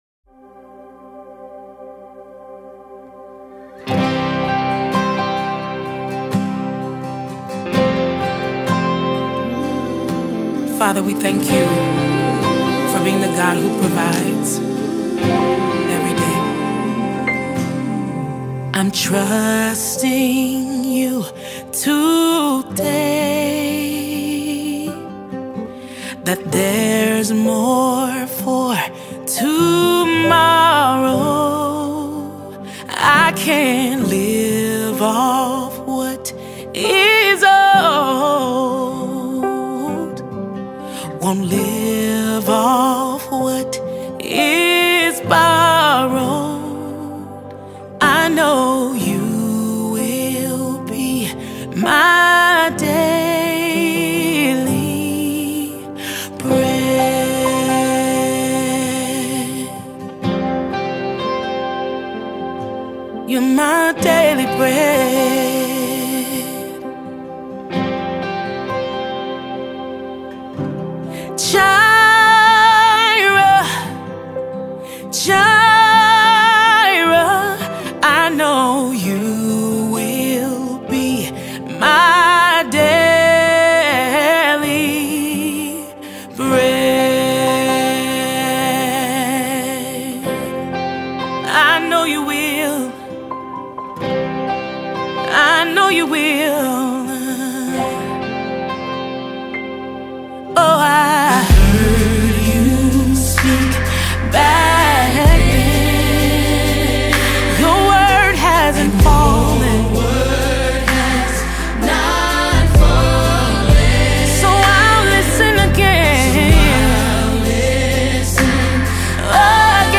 57 просмотров 21 прослушиваний 2 скачивания BPM: 64